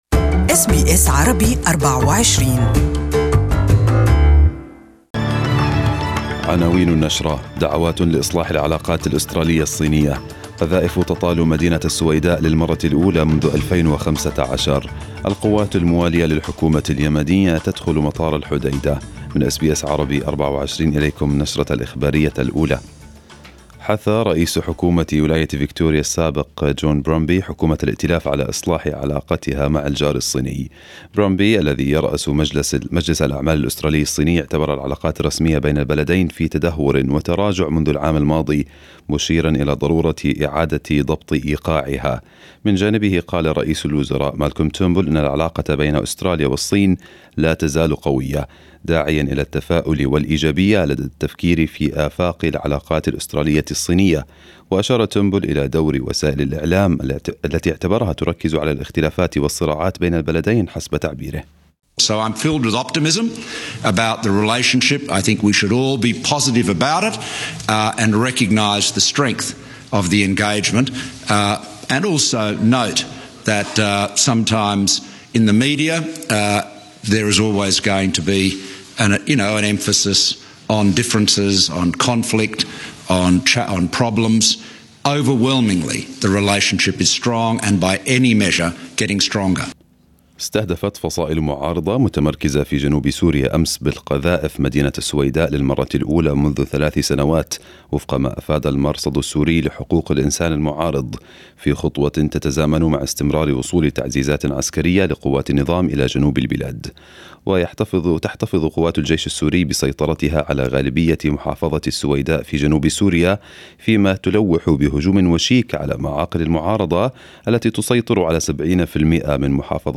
Arabic News Bulletin 20/06/2018